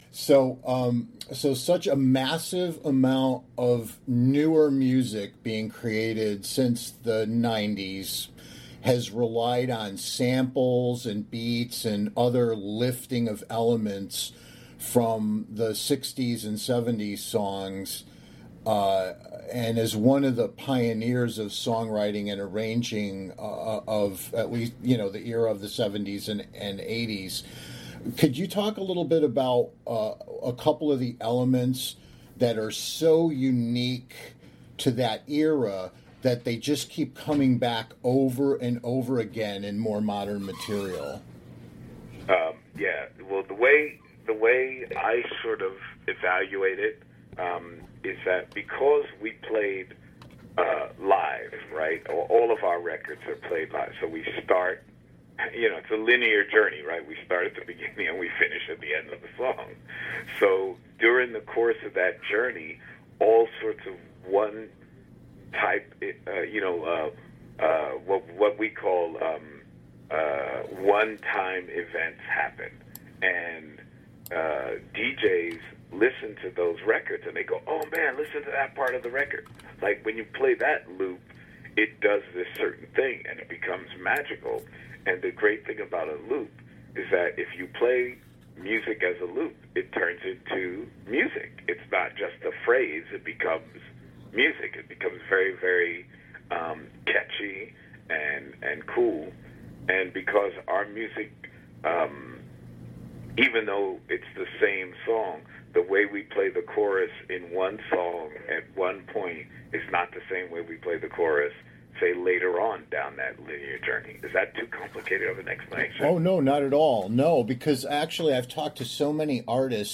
Nile Rodgers Interview